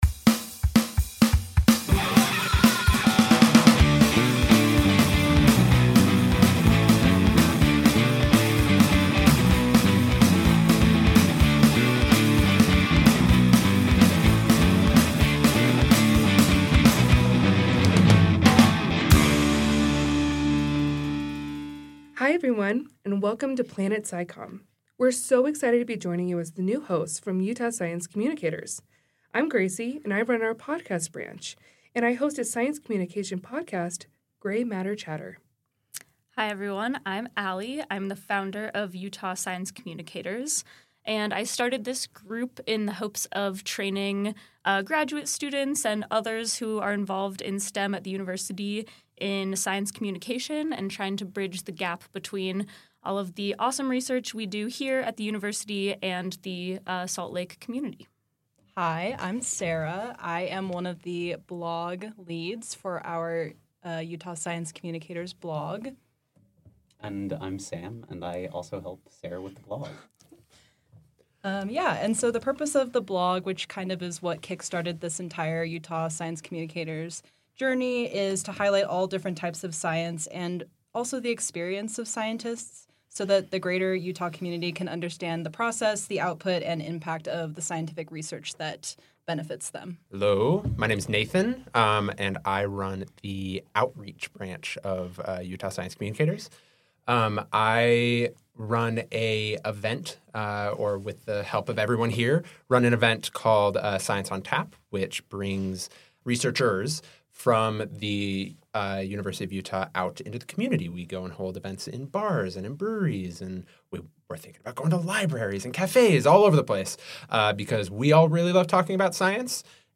Welcome to the Planet SciComm podcast, where a science communication enthusiast, practitioner, and researcher talk about science communication and any other musings that come to mind!